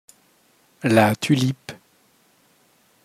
• Произношение: Байредо Парфюмери Ла Тюльп